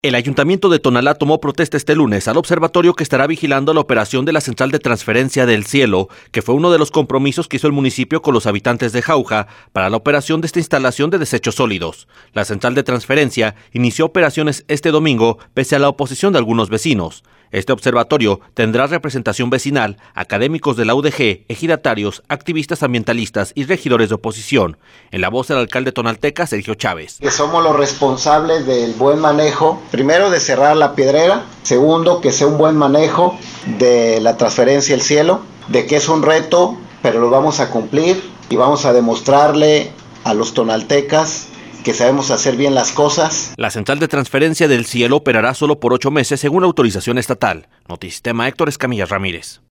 En la voz el alcalde tonalteca, Sergio Chávez.